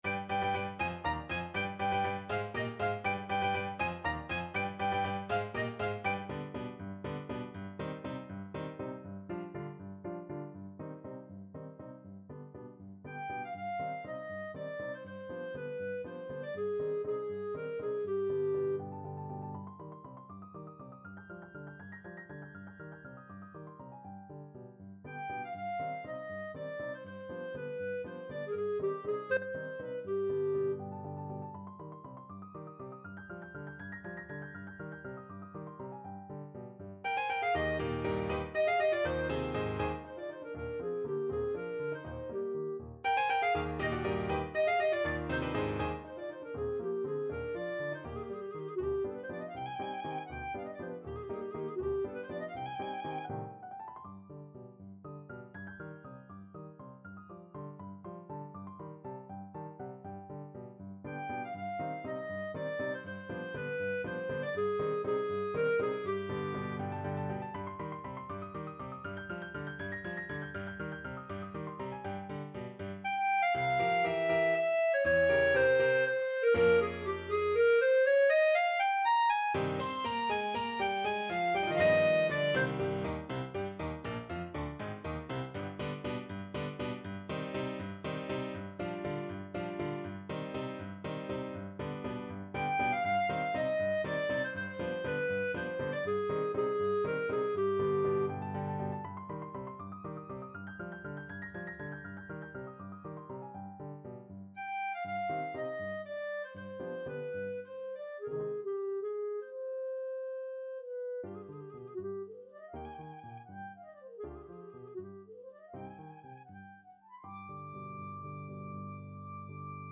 3/8 (View more 3/8 Music)
Allegro vivo (.=80) (View more music marked Allegro)
Classical (View more Classical Clarinet Music)